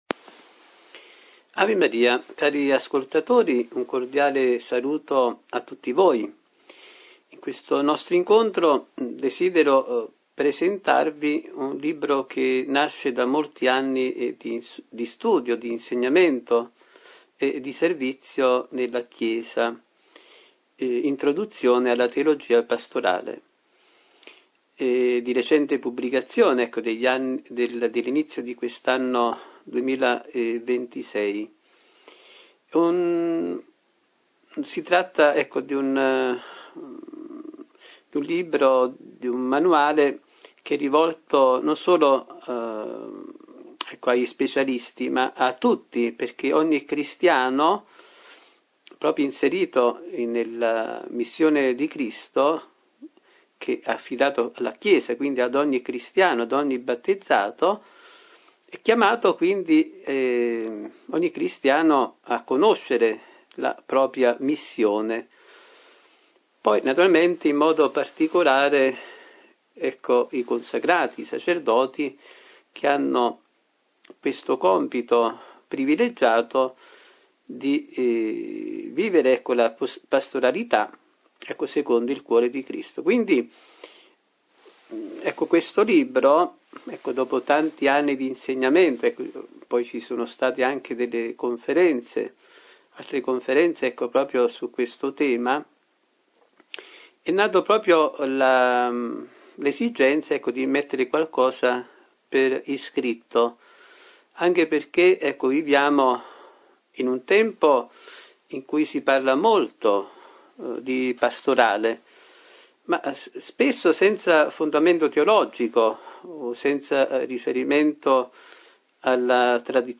Genere: Catechesi domenicali.